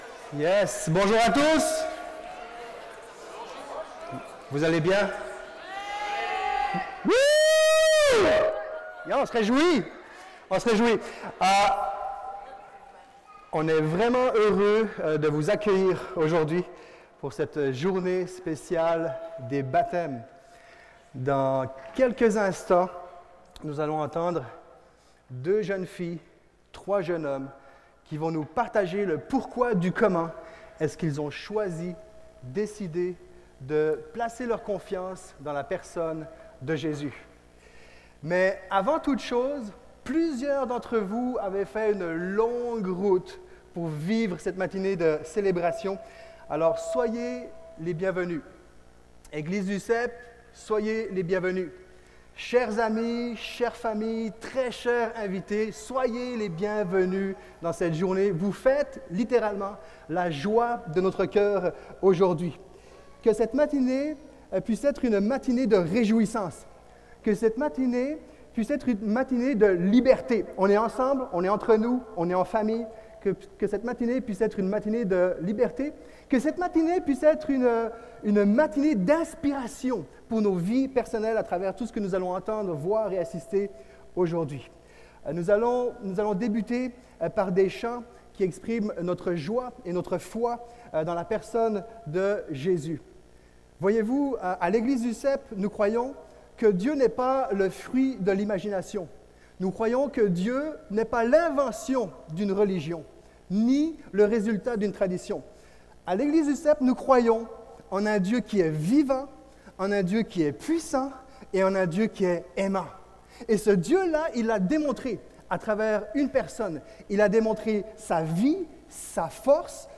Culte de baptêmes du 17 février